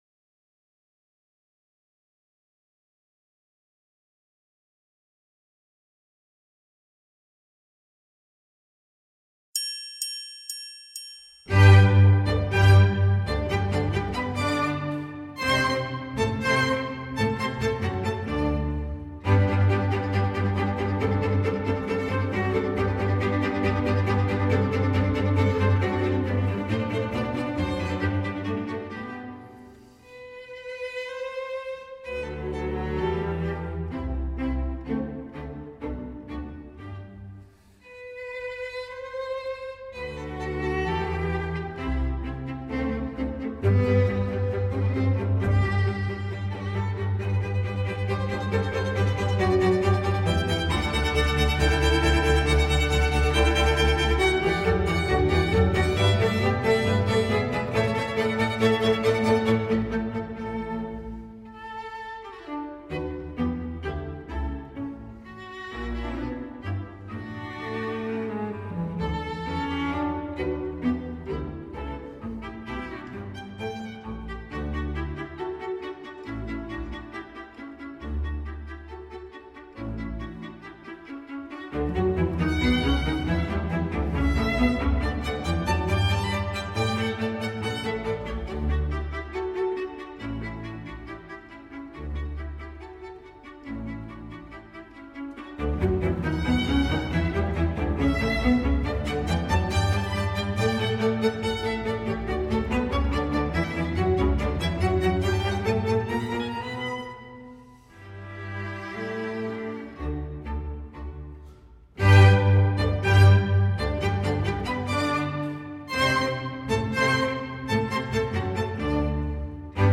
El acompañamiento:
w-a-mozart-allegro-theme-from-eine-kleine-nachtmusik-accompaniment-for-flute-violine-128-ytshorts.savetube.me_.mp3